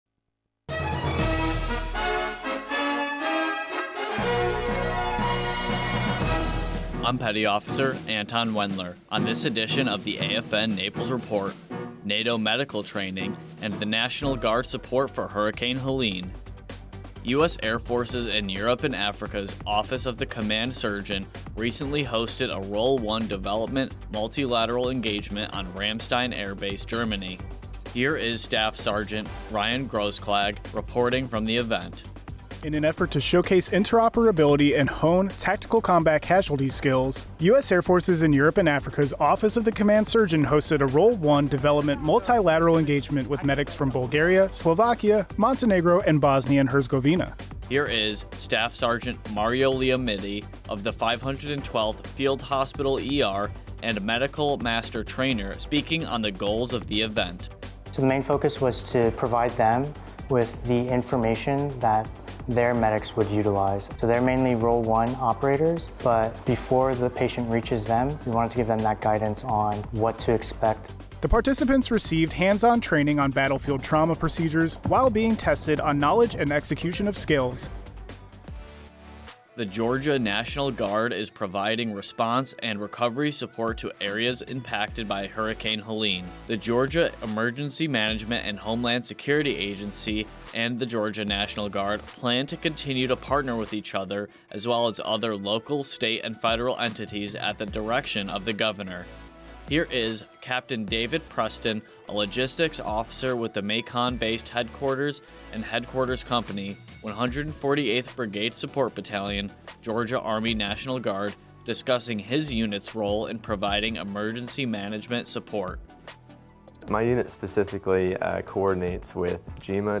Radio News